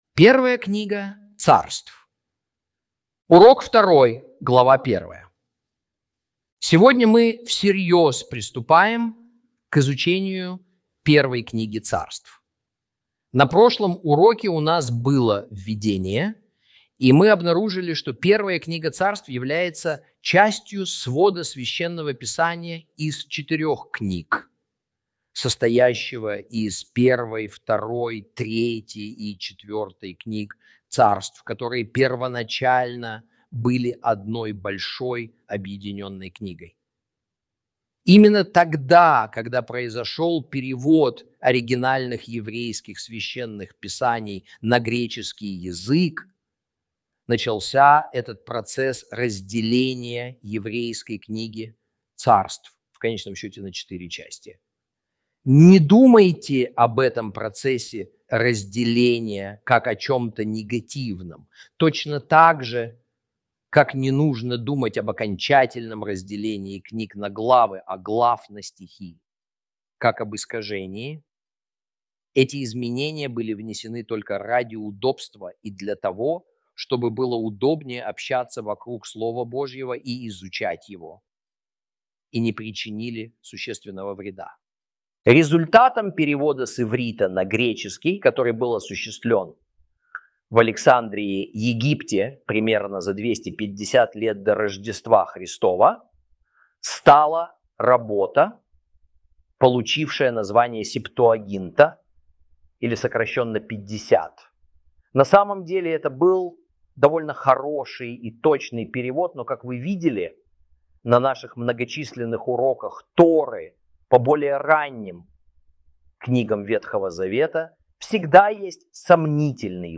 Урок 2 - 1 Царств Ch 1 - Torah Class